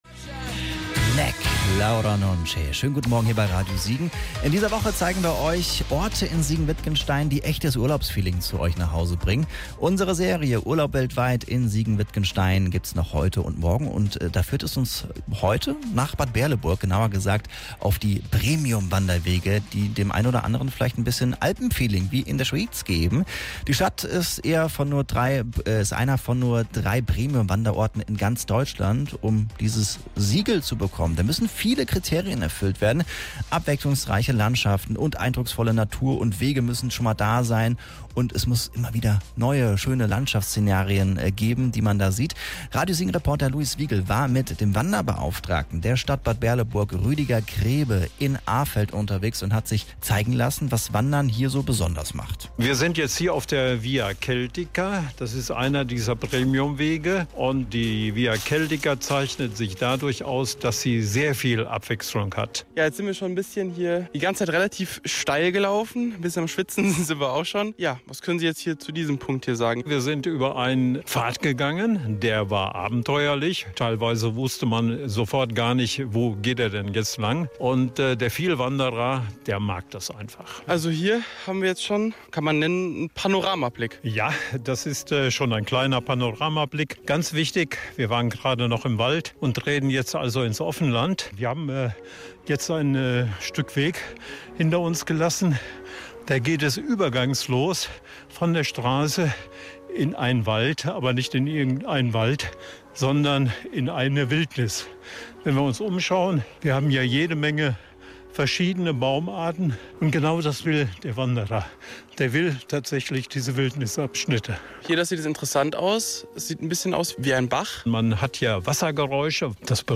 nach Arfeld zum wandern, wie in der Schweiz.